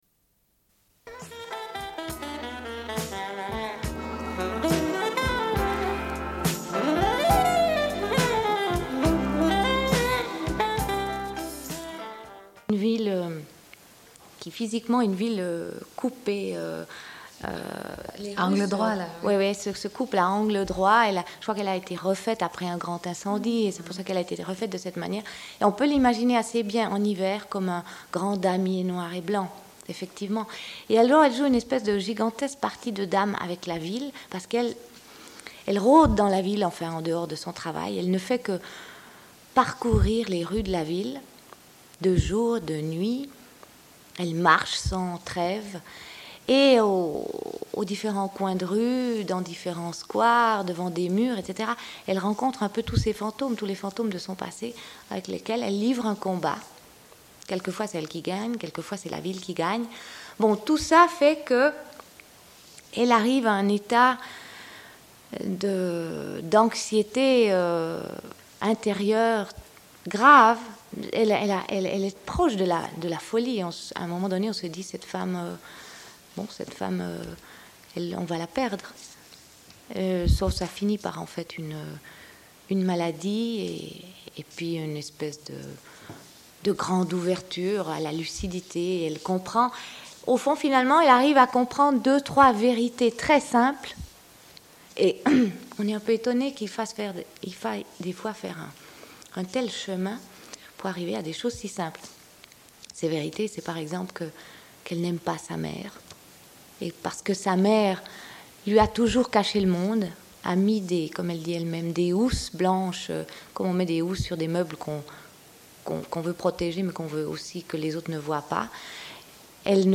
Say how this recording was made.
Une cassette audio, face B00:46:47 Diffusion d'un entretien en différé.